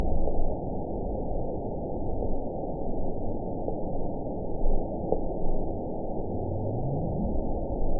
event 911101 date 02/10/22 time 03:41:32 GMT (3 years, 3 months ago) score 9.11 location TSS-AB01 detected by nrw target species NRW annotations +NRW Spectrogram: Frequency (kHz) vs. Time (s) audio not available .wav